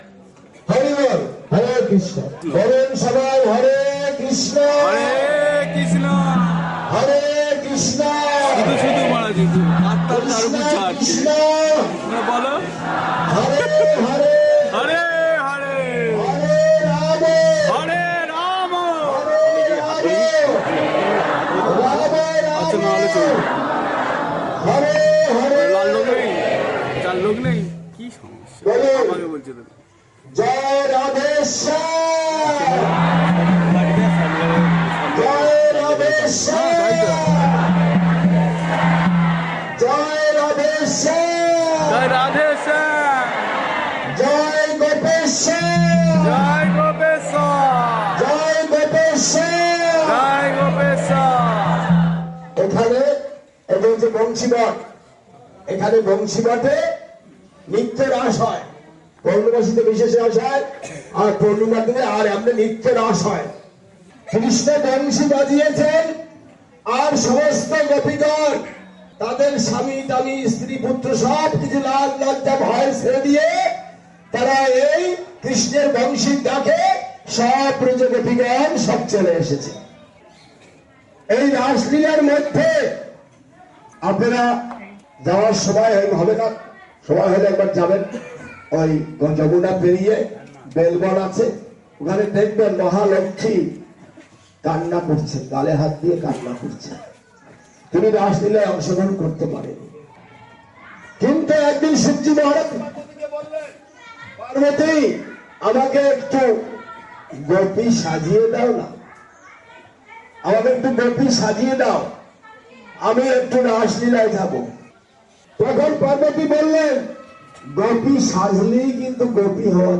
Sri Vrindavan Dham parikrama